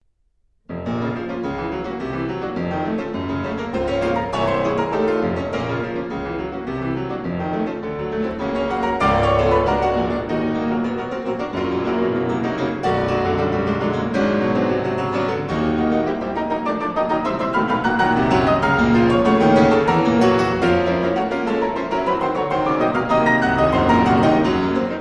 Prelude No. 21 in B flat major: Andante piacevole